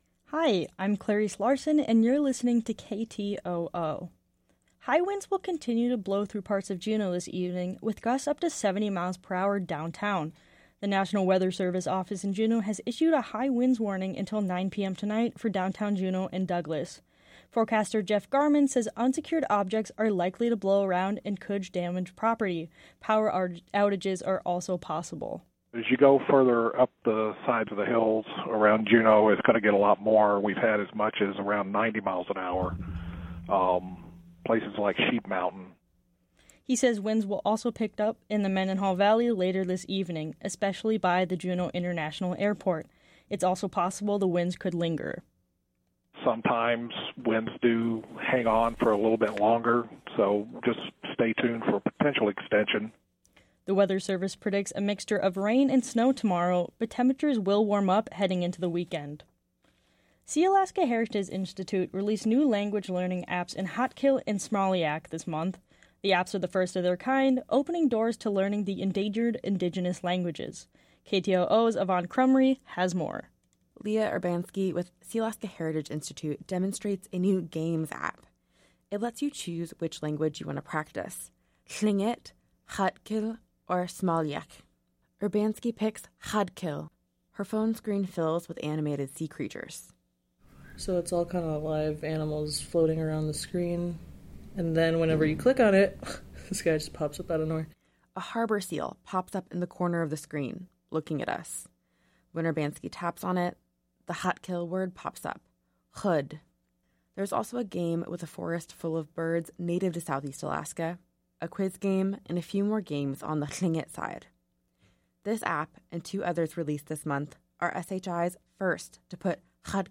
Newscast - Wednesday, Dec. 27, 2023 - UpNitro